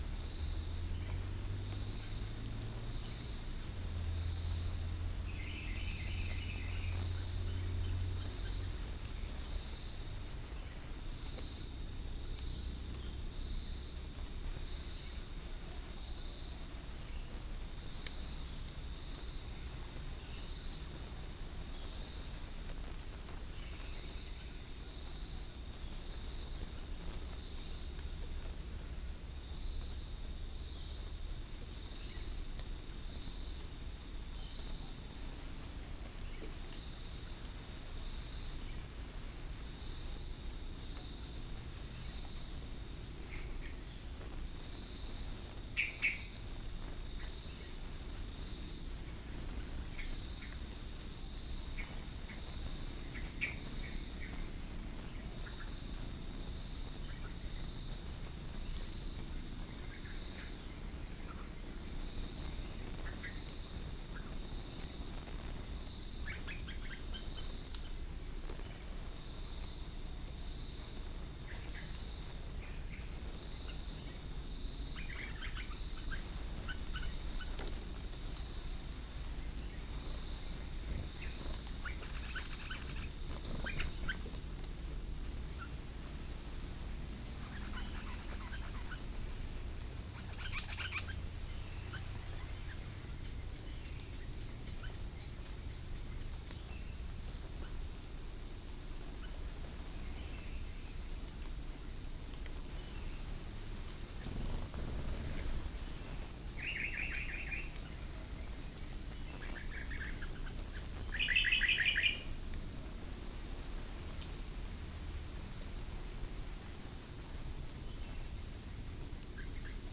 Upland plots dry season 2013
Chalcophaps indica
Pycnonotus goiavier
Todiramphus chloris
Dicaeum trigonostigma